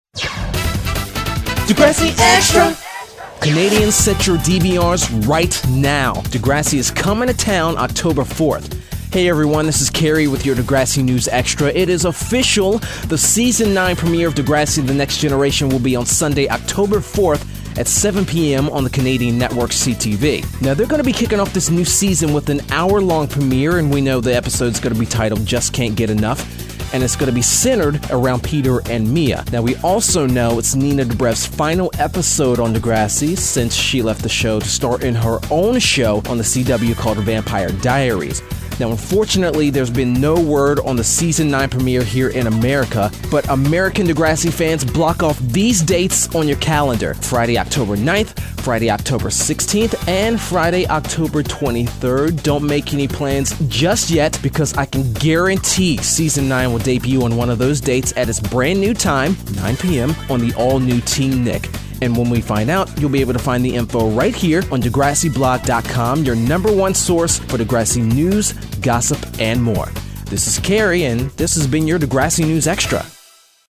An audio recap of the blog topic…it’s Degrassi News Extra!